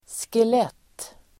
Uttal: [skel'et:]